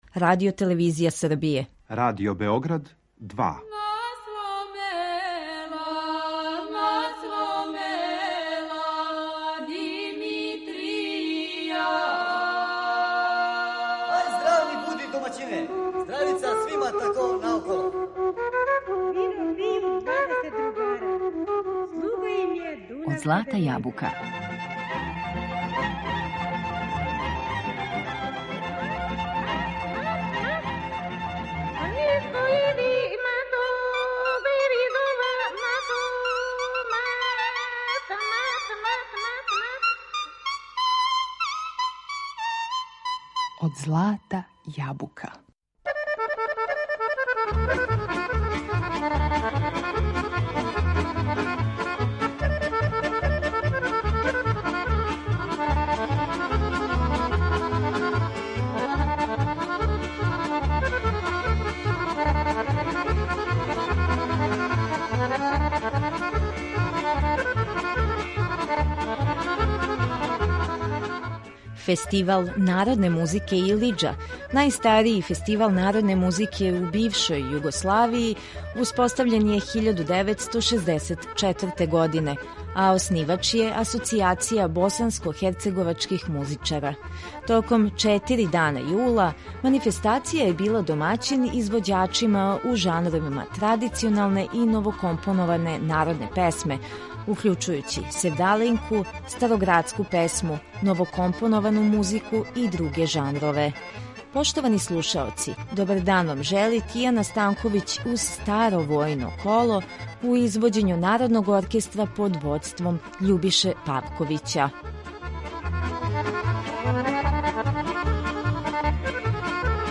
На репертоару је избор песама компонованих у народном духу истакнутих вокалних солиста.